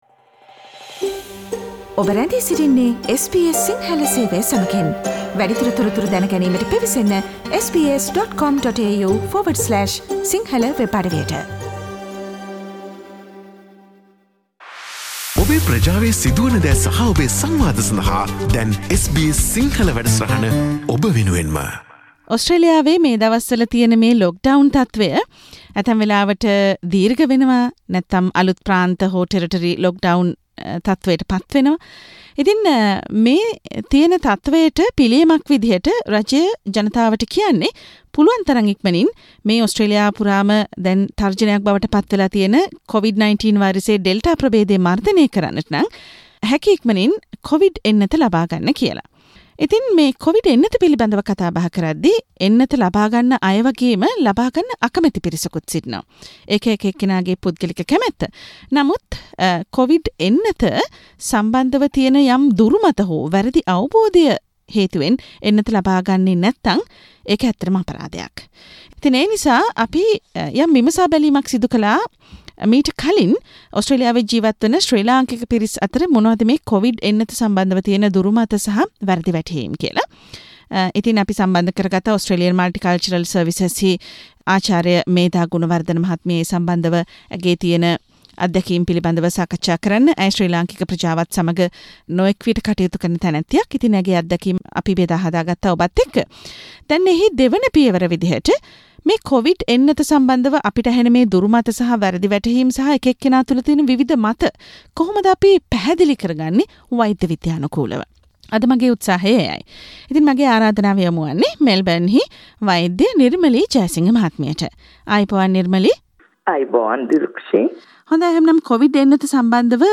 COVID එන්නත් ගැන කවුරුත් කතා කරන විවිද ආන්දෝලනාත්මක මති මතාන්තර ගැන වෛද්‍ය විද්‍යාත්මකව පැහැදිලි කිරීමක් සිදුකෙරෙන මෙම සාකච්චාවට සවන්දෙන්න.